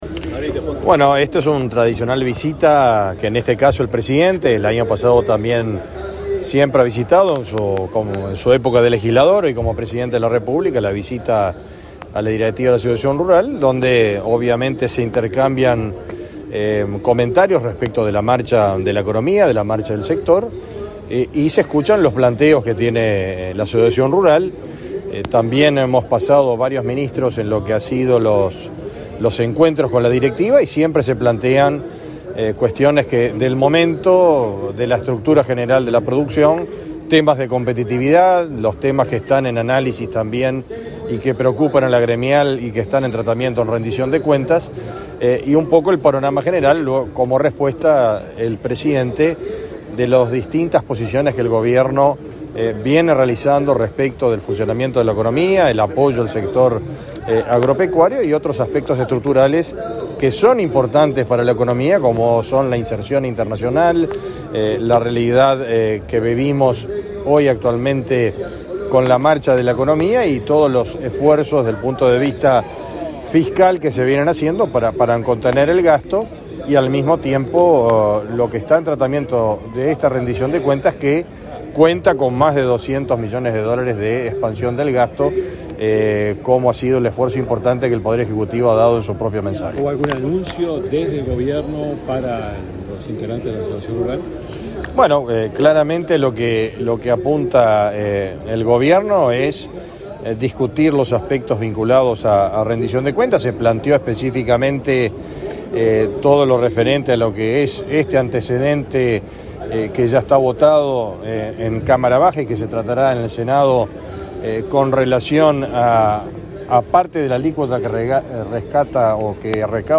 Declaraciones a la prensa del ministro de Ganadería, Fernando Mattos | Presidencia Uruguay